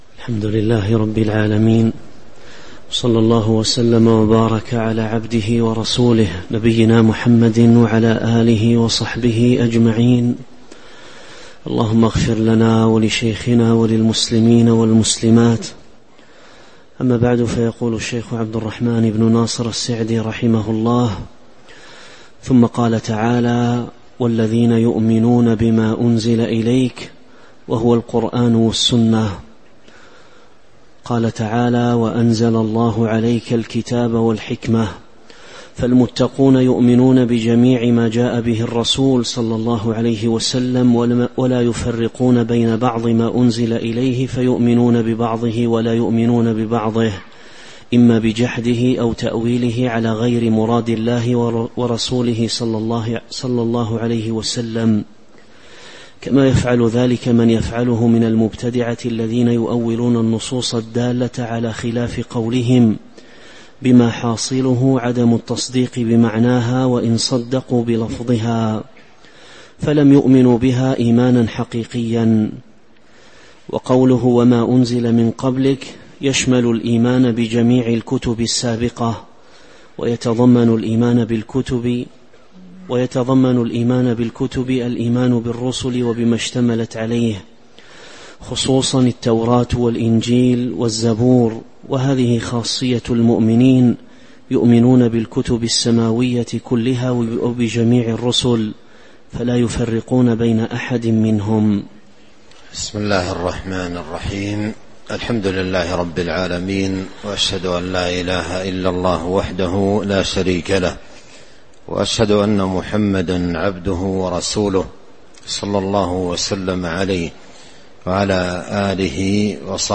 تاريخ النشر ٢٣ ربيع الأول ١٤٤٦ هـ المكان: المسجد النبوي الشيخ: فضيلة الشيخ عبد الرزاق بن عبد المحسن البدر فضيلة الشيخ عبد الرزاق بن عبد المحسن البدر تفسير سورة البقرة من آية 04-05 (03)